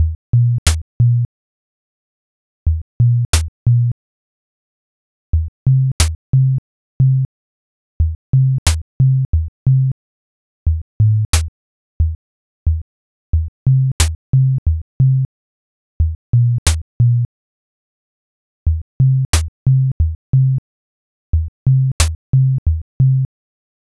walk_run_hiphop_ChatGPT(260116).wav
표의 수치를 바로 비트로 만들어 파일을 올려주는 ChatGPT